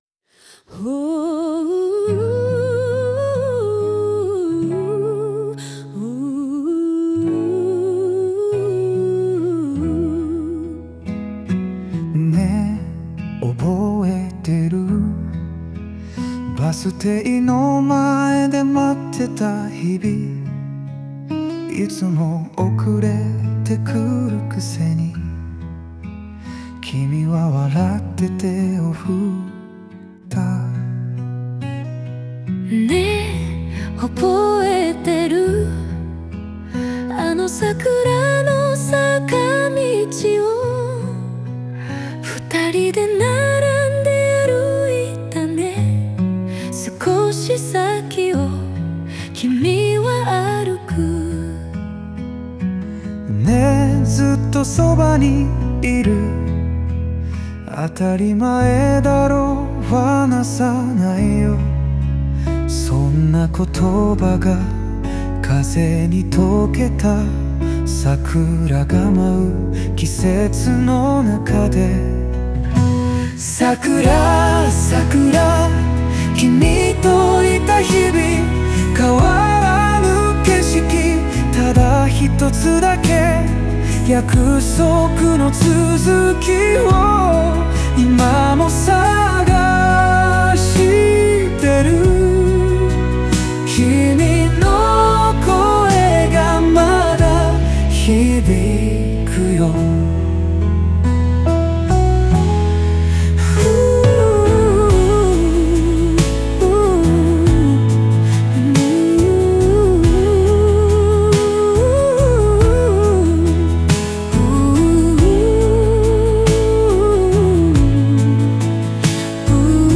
オリジナル曲♪
この歌詞は、男女の視点が交互に展開するデュエット形式で描かれています。